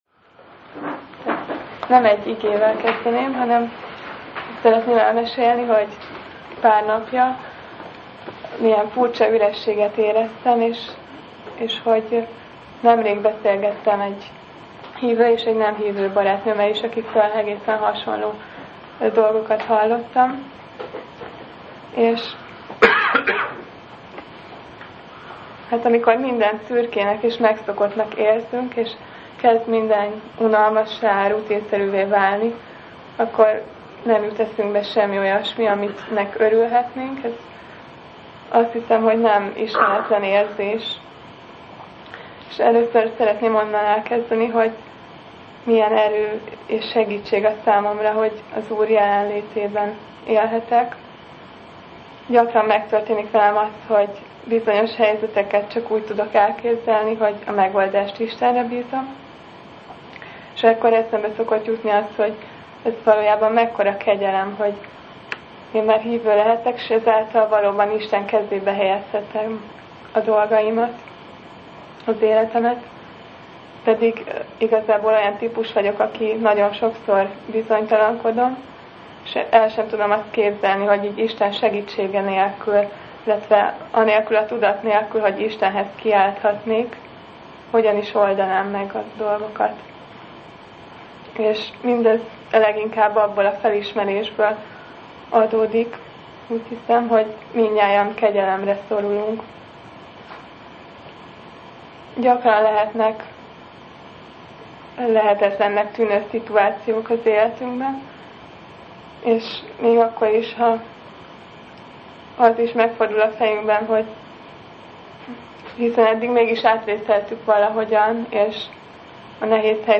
Bizonyságtétel
Az alkalom az ?szi hétvégén készült, krónika nem íratott.